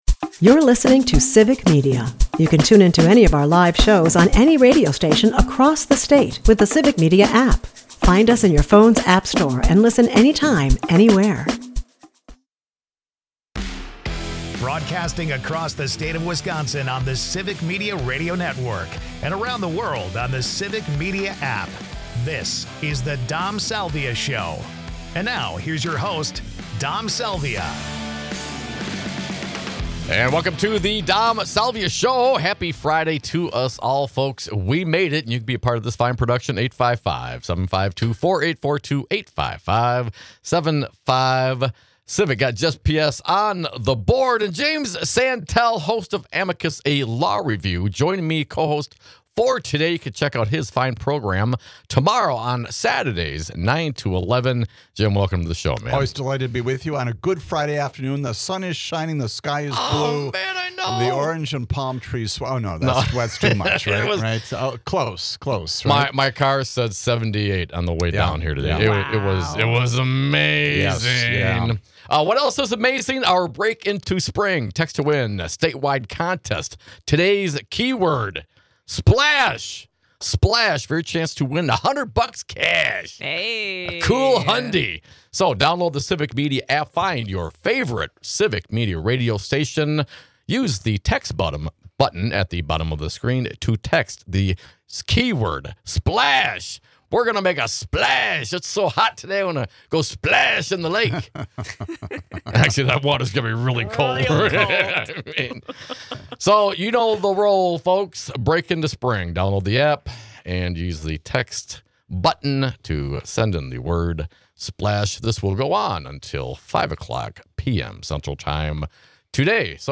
Jim Santelle 3/28/2025 Listen Share Jim Santelle, host of Amicus: A Law Review , joins us as cohost for the day.